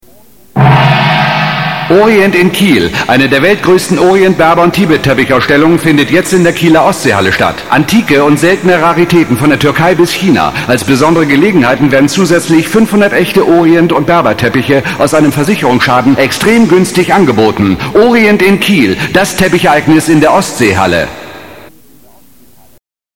deutscher Schauspieler und Sprecher.
norddeutsch
Sprechprobe: eLearning (Muttersprache):